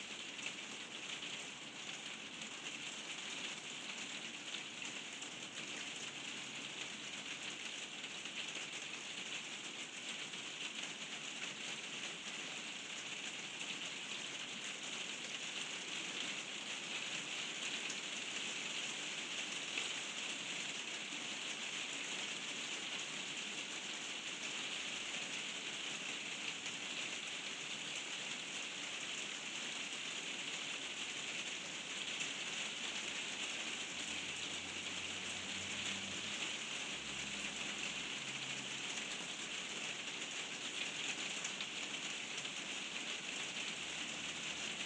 Rainyboo in my 'sun' room